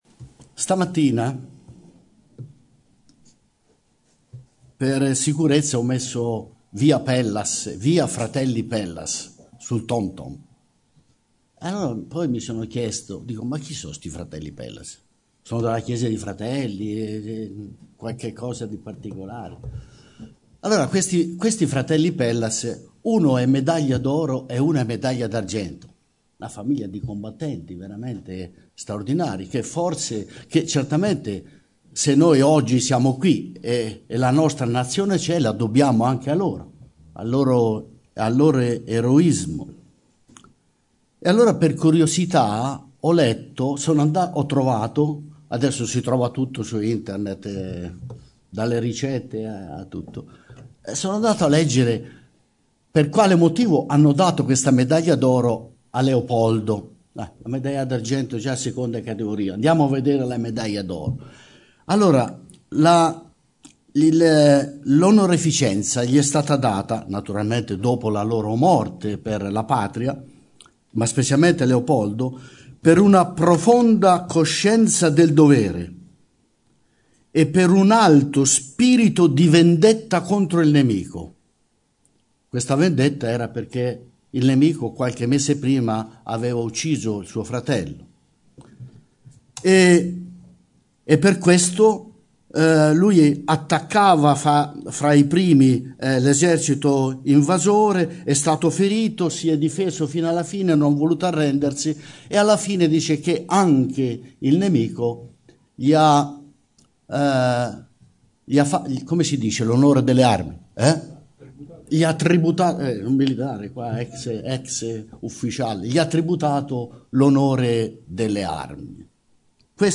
Bible Text: Luca 15:11_32 | Preacher
Oratore